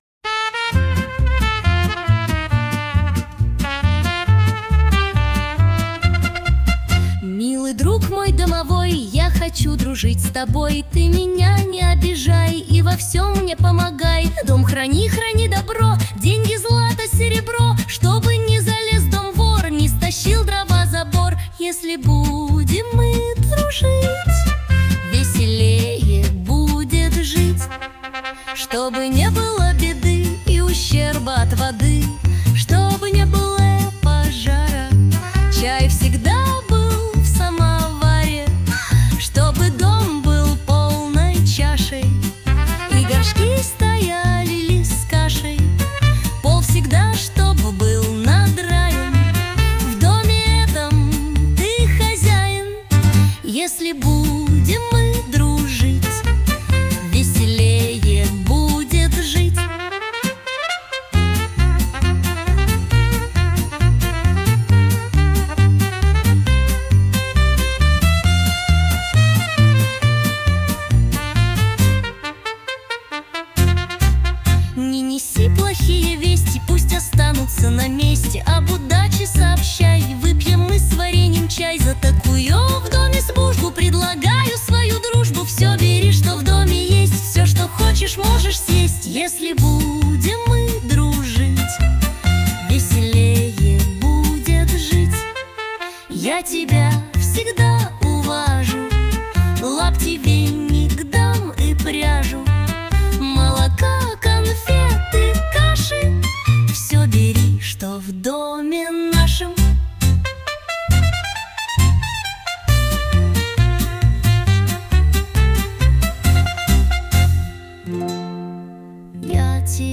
• Аранжировка: Ai
• Жанр: Фолк